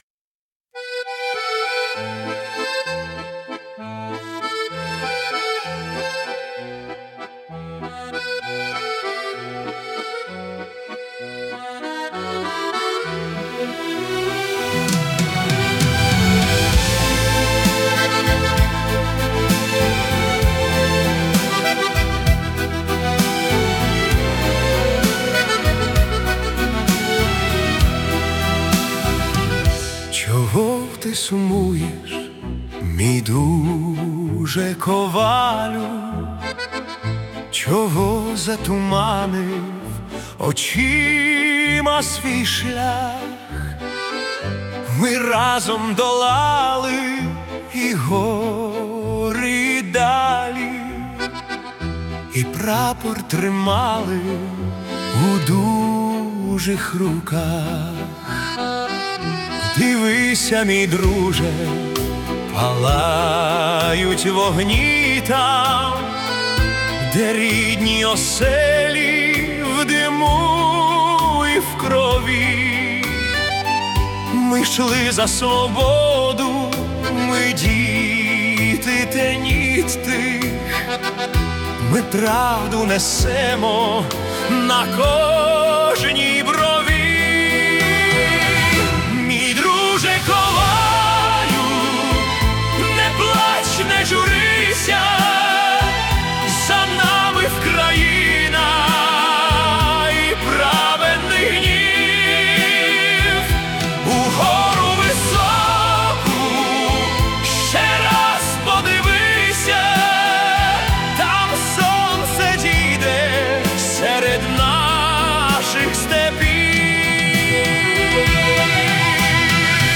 Ukrainian Retro Waltz